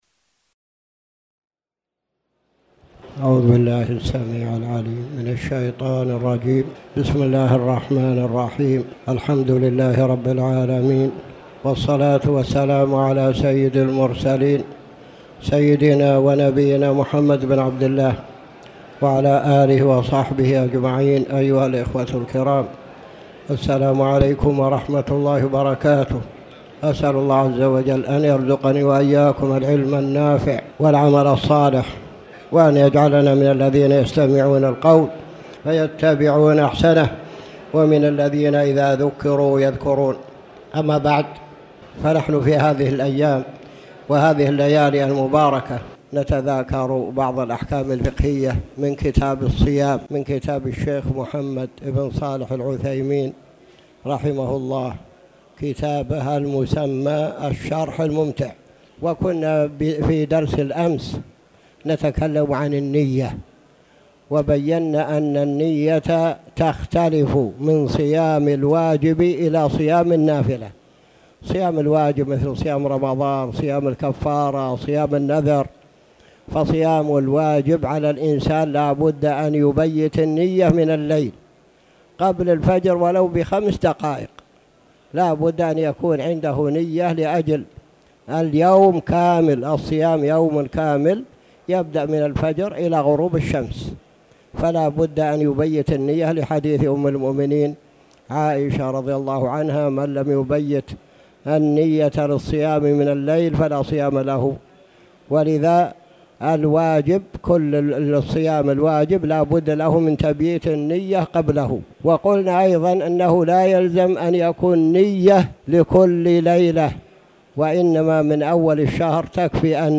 تاريخ النشر ١٩ جمادى الأولى ١٤٣٩ هـ المكان: المسجد الحرام الشيخ